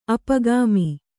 ♪ apagāmi